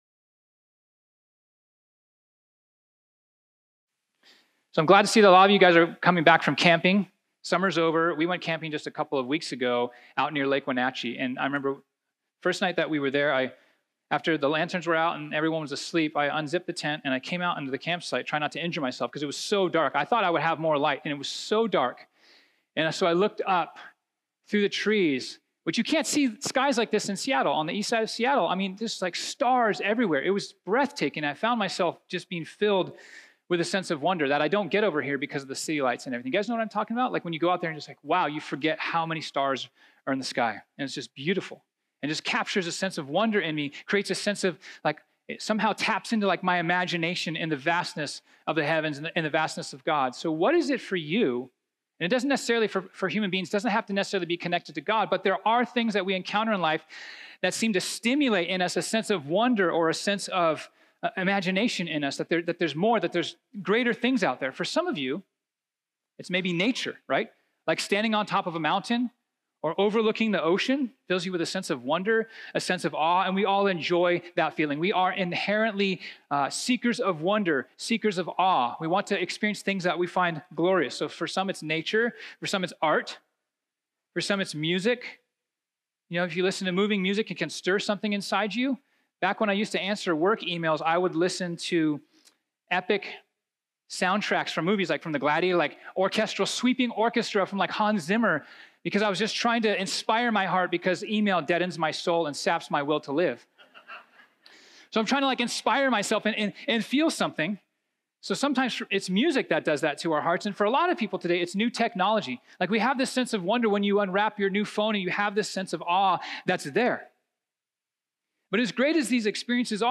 This sermon was originally preached on Sunday, September 8, 2019.